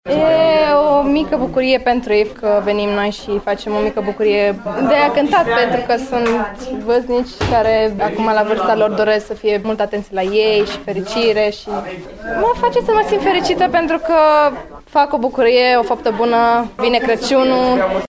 Cu lacrimi în ochi